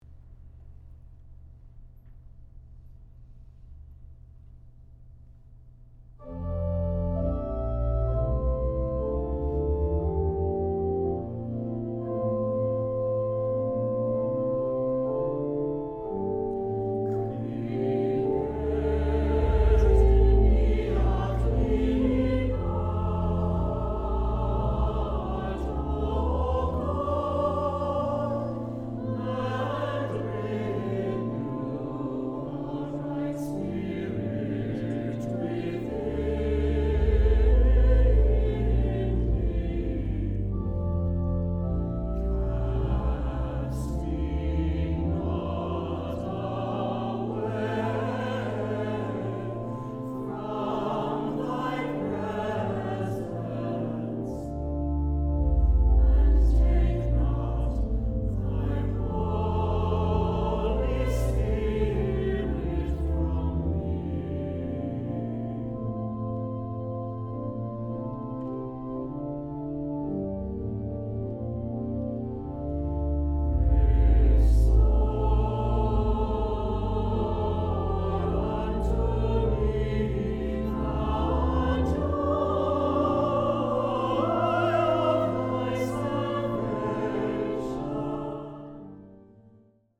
easy anthems
for unison choir or solo voice and organ accompaniment
Unison